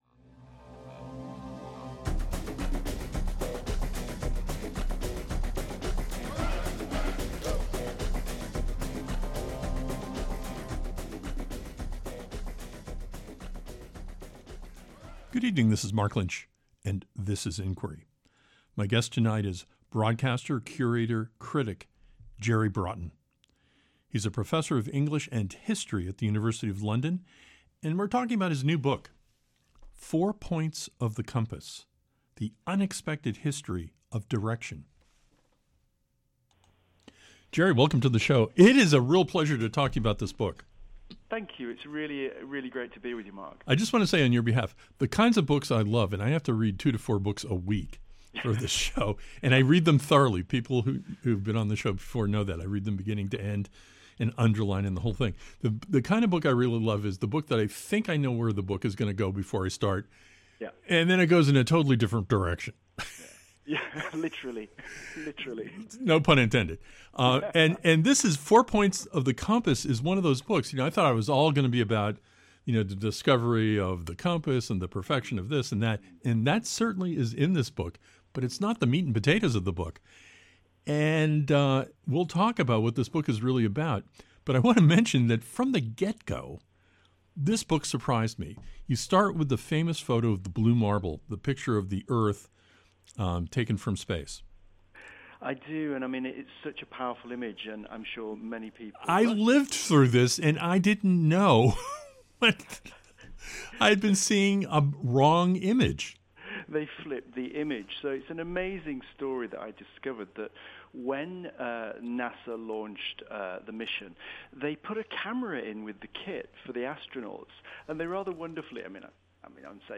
North, South, East, and West are much more than simply directions on a compass. On this episode of Inquiry, we talk with broadcaster, curator, and critic JERRY BROTTON.